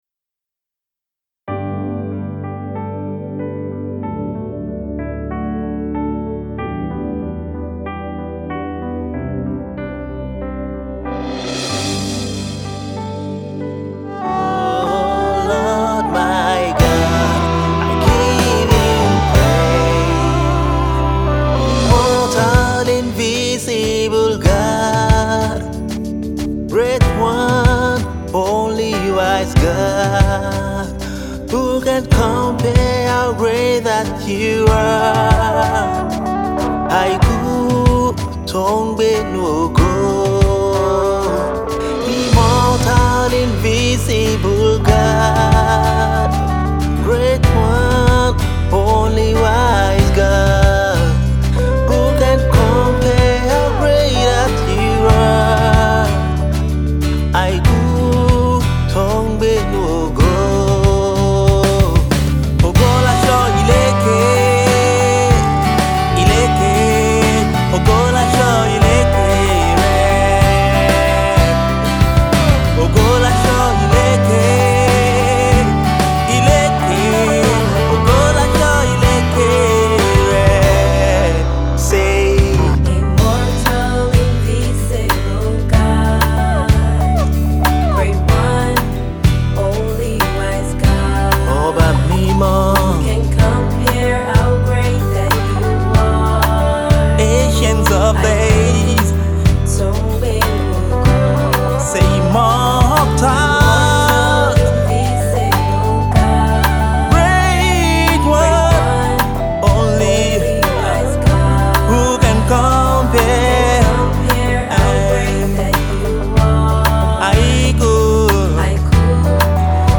Gospel Music
simple but spirit-filled song